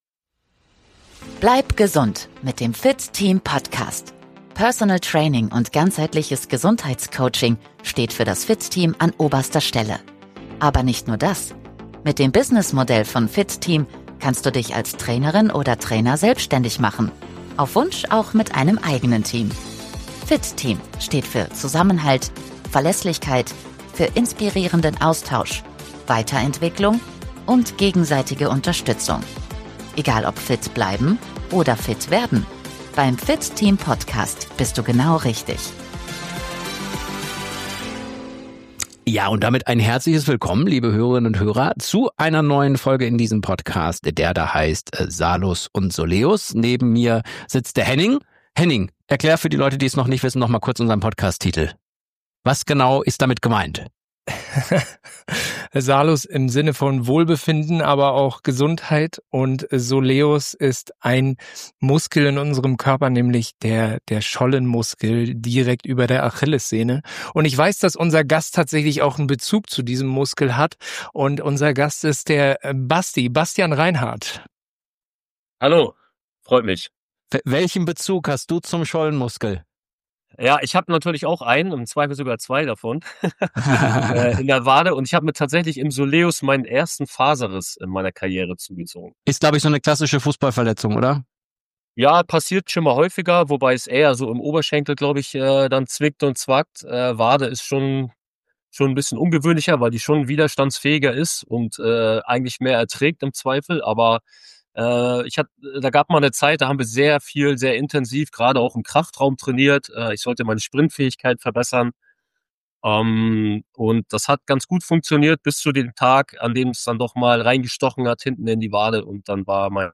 Ein Gespräch über Disziplin, DDR-Sportschulen, Individualtraining und die Kraft, sich immer wieder neu zu erfinden – mit vielen Parallelen zum echten Leben abseits des Platzes.